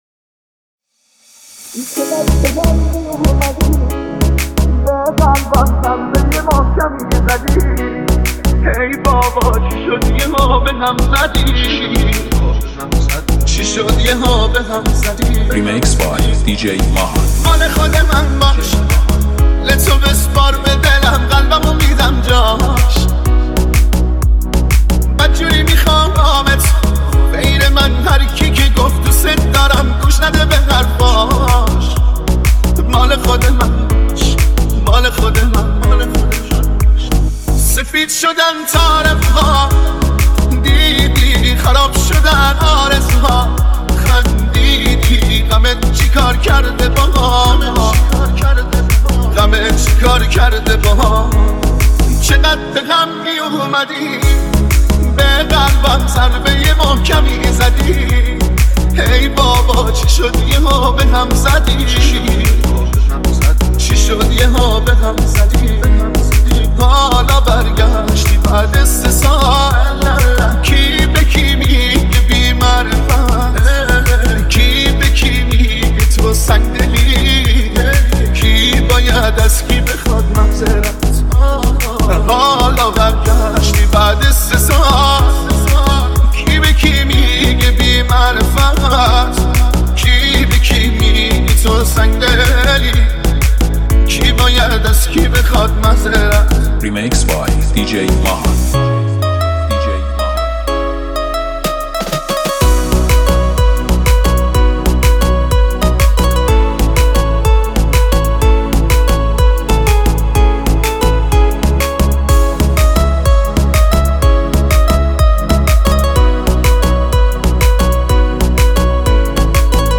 ریمیکس سوم